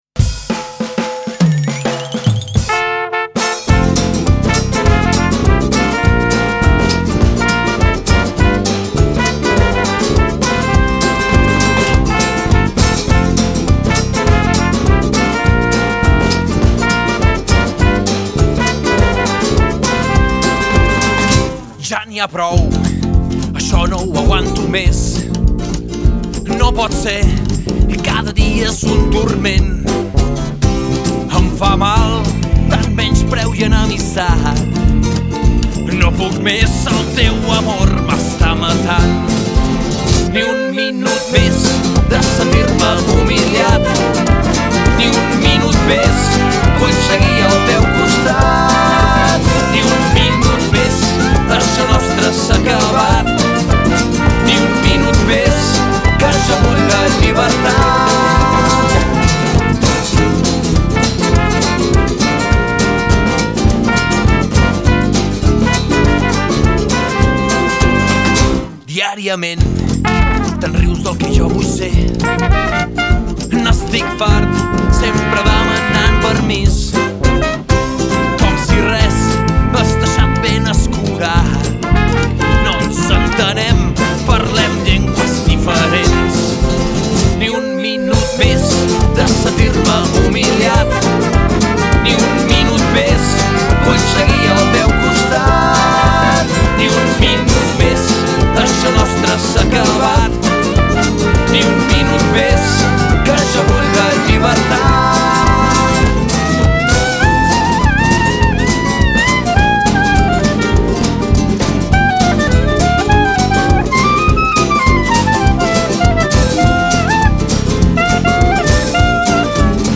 La colla rumbera d'Igualada celebra cinc anys de vida
És un tema amb base rumbera però un curiós aire de swing: